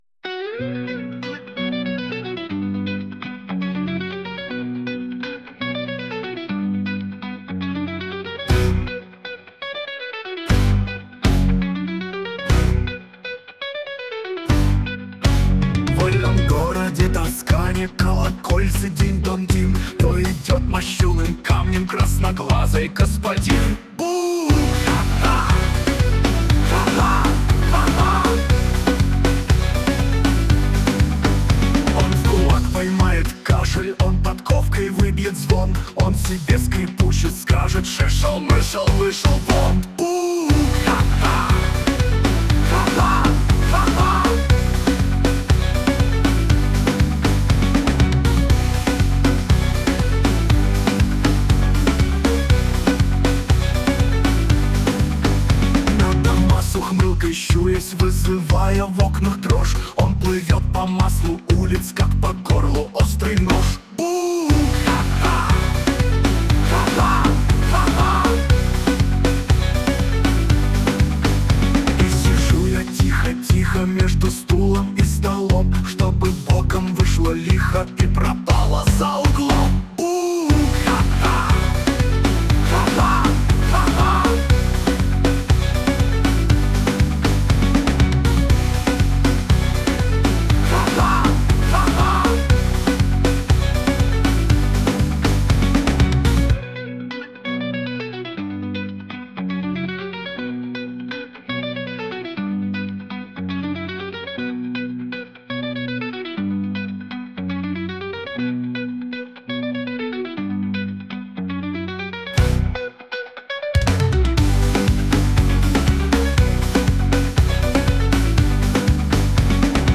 Стихи, понятно, древние мои, музыка и голос, понятно, нейросетевые.
Нейросеть где-то понимает "ё" в простой "е", а где-то нет.